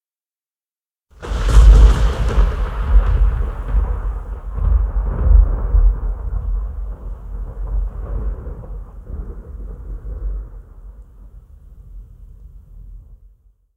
AS-SFX-Thunder 2.ogg